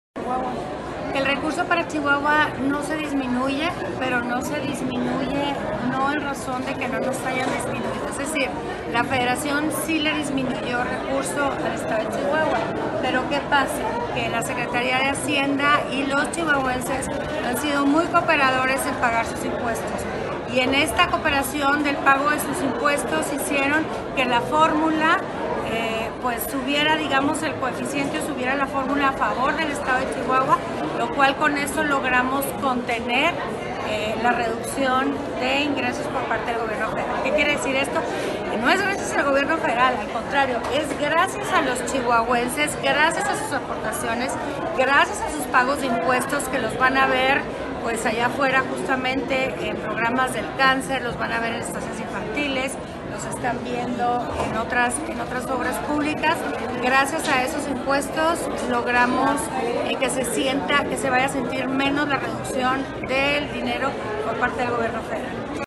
AUDIO: MARÍA EUGENIA CAMPOS, GOBERNADORA DEL ESTADO DE CHIHUAHUA